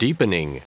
Prononciation du mot deepening en anglais (fichier audio)
Prononciation du mot : deepening